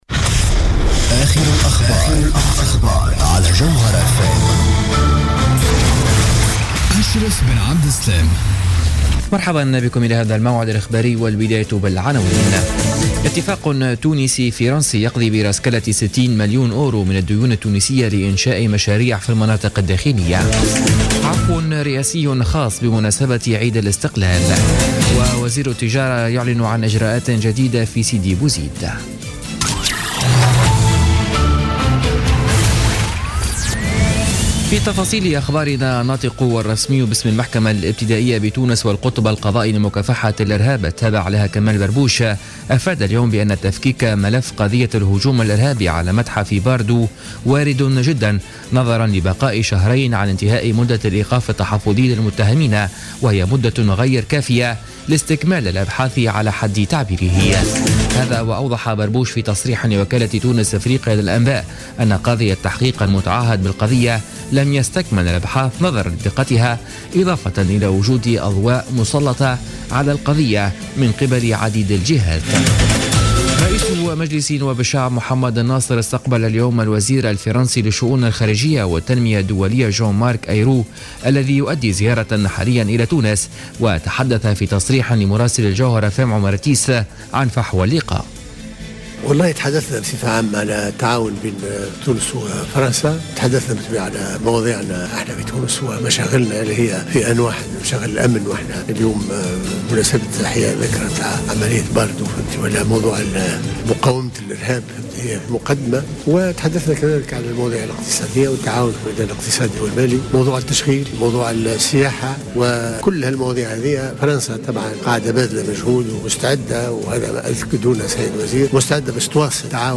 نشرة أخبار السابعة مساءً ليوم الجمعة 18 مارس 2016